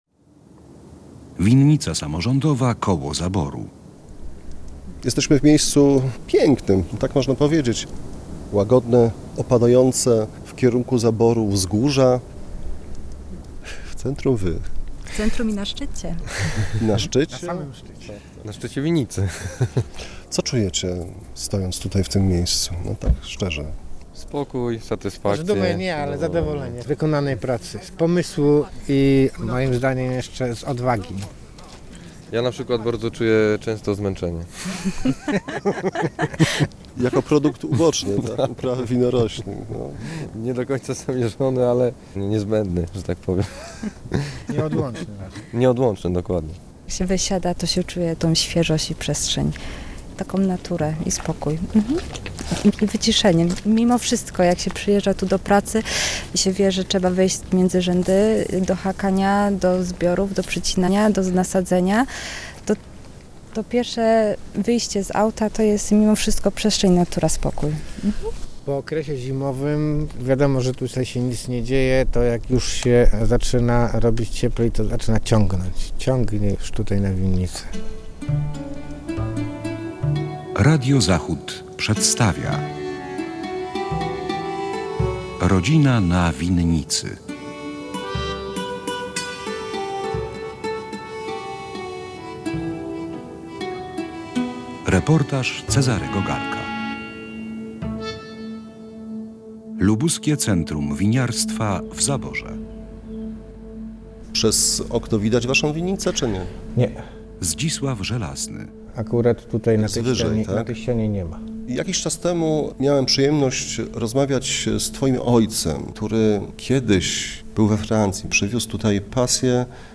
„Rodzina na winnicy”- posłuchaj reportażu